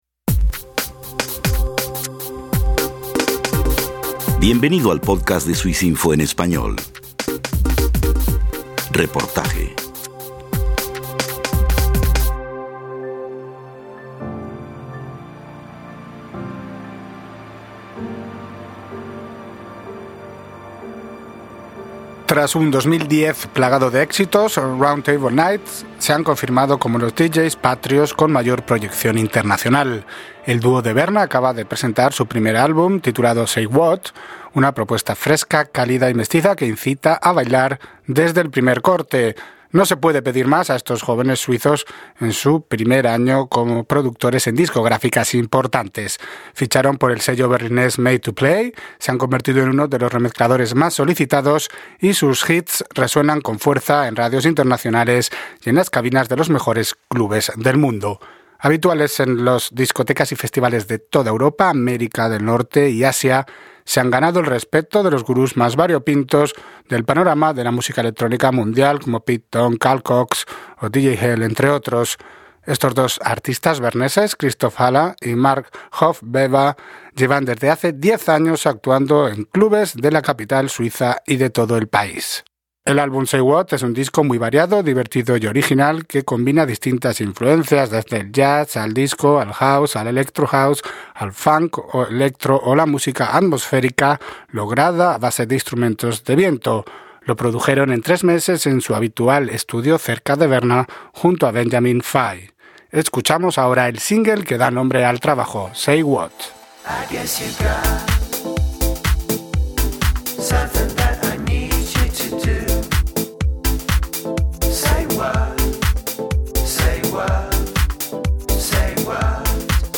Reportaje de RTK